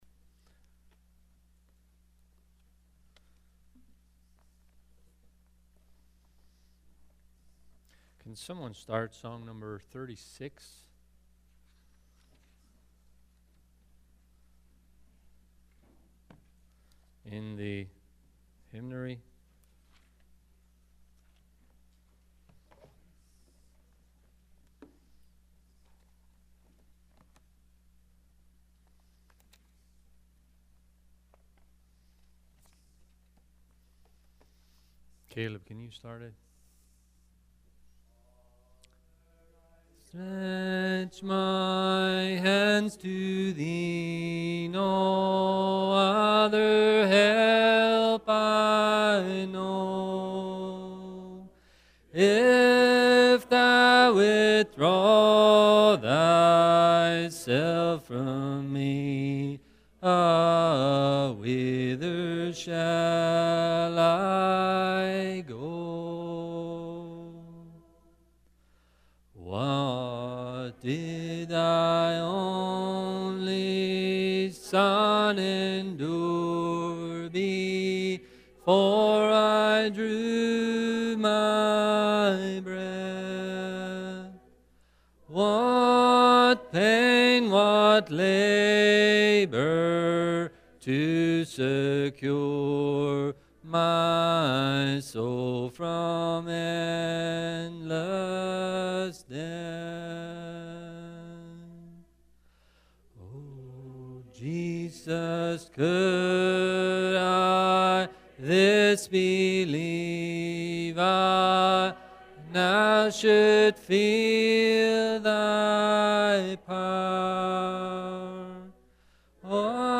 Bible School 2020 Service Type: Midweek Meeting Speaker